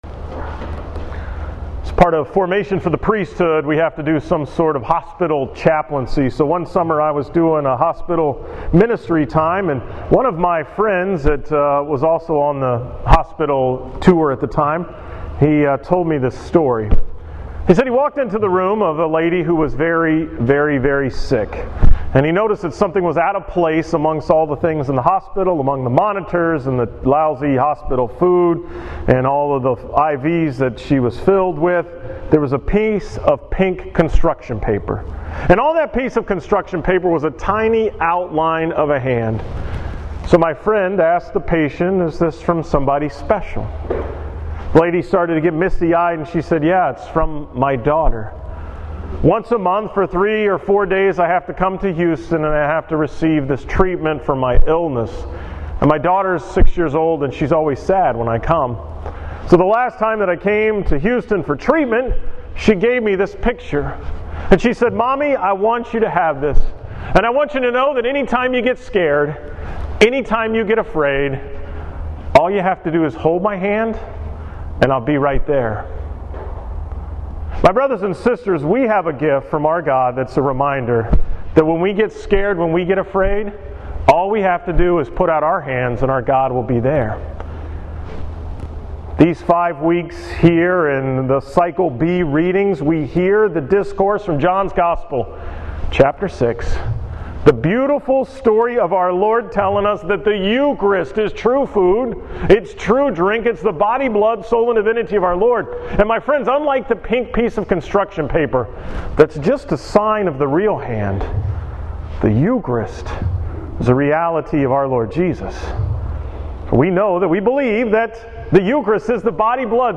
From the 9 am Mass on Sunday, August 2nd at St. John of the Cross in New Caney.
Wow very nice Homily.